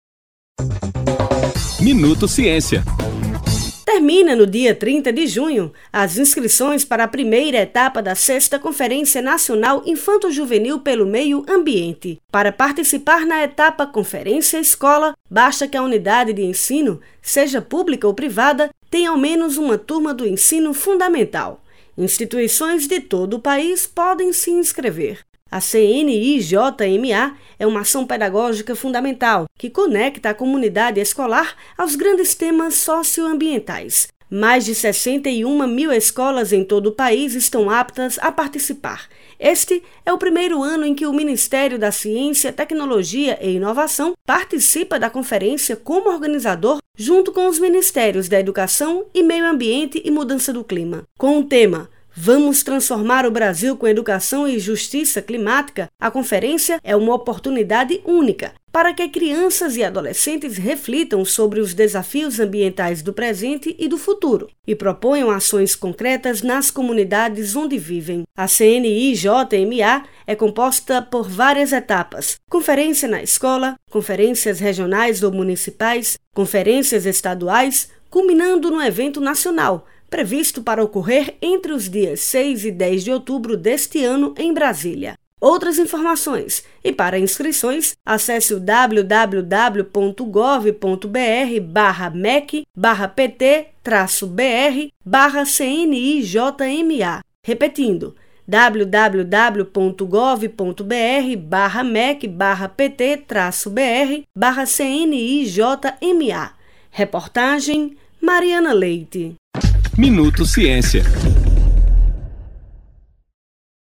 Evento gratuito ocorrerá ao longo de dois dias, com um deles voltado especialmente para grupos de estudantes; escolas interessadas devem se inscrever até este domingo (09). Saiba mais com a repórter